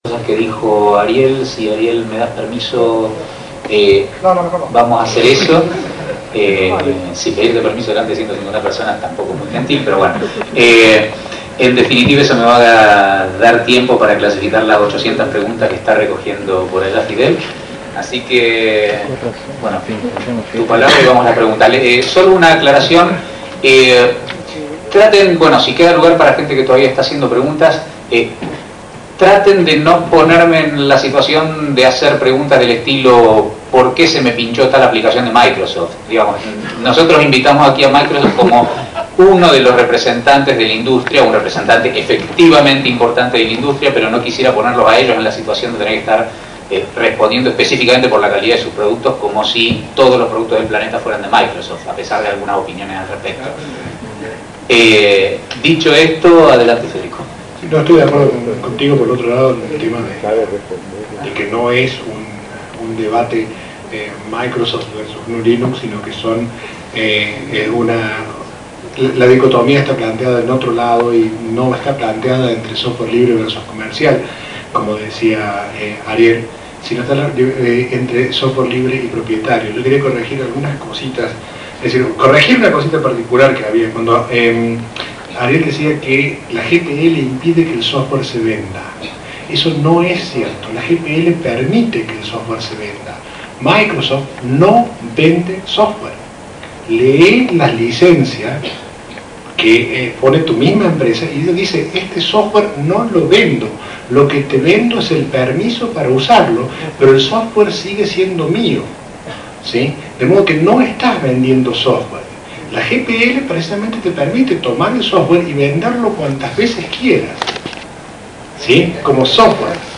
Debate Software Libre vs. Software Propietario
El debate no se pudo grabar todo aunque solamente se perdieron 5 minutos del comienzo y 10 del final Primero expuso la gente de Via Libre, luego la gente de M$ y después los representantes de cada parte respondieron preguntas del publico (esta es la parte más rica del debate).